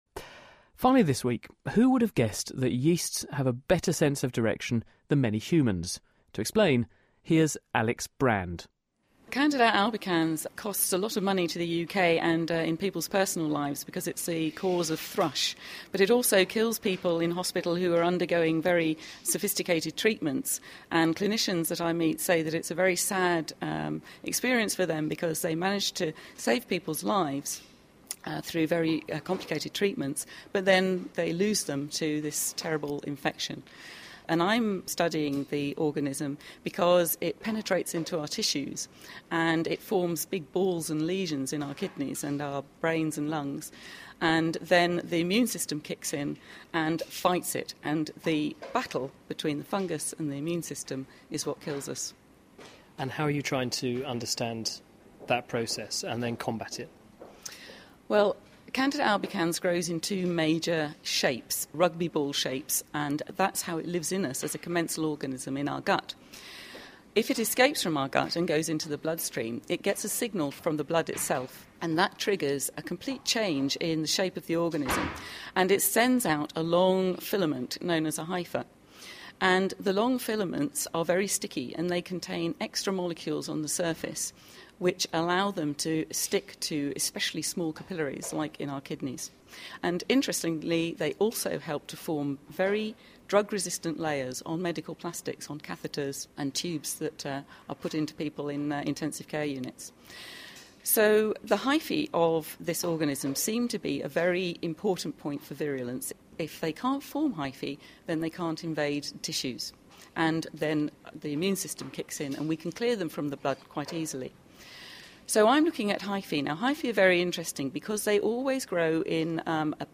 Interviews with Scientists
Interviews about medicine, science, technology and engineering with scientists and researchers internationally...